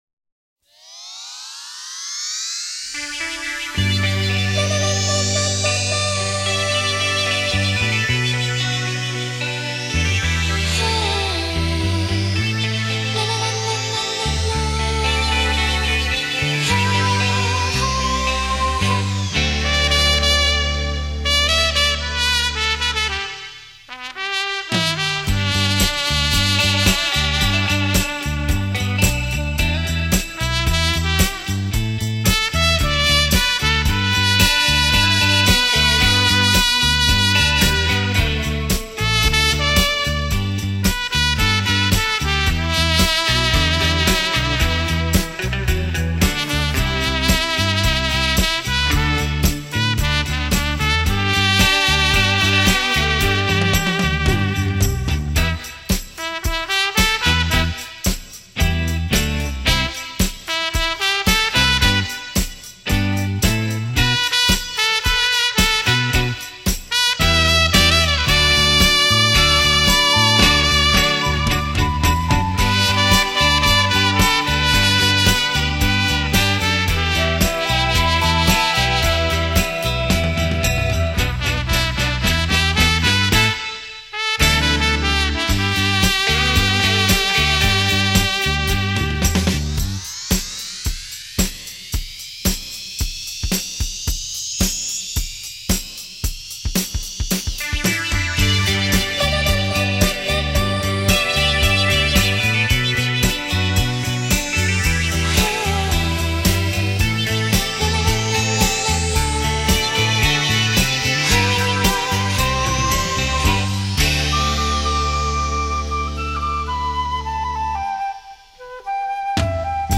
烂辉煌的小号吹奏，一如橙色给人心暖融融的感觉　　。